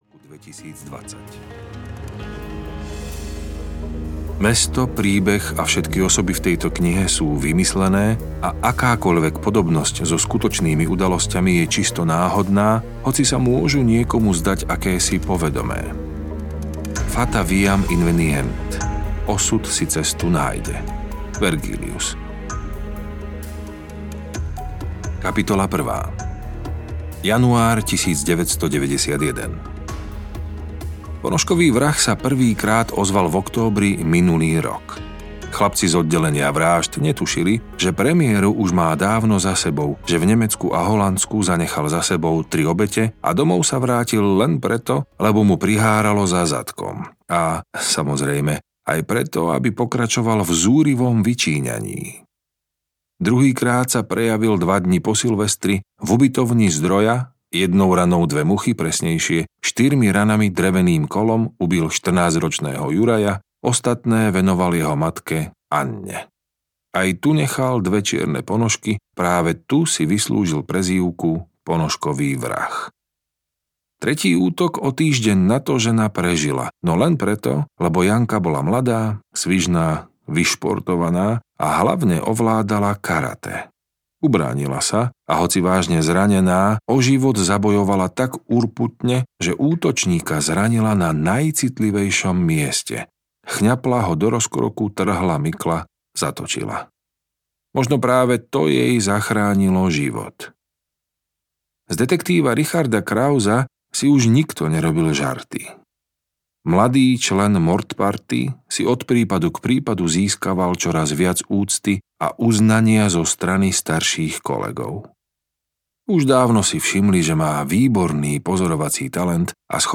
V tieni audiokniha
Ukázka z knihy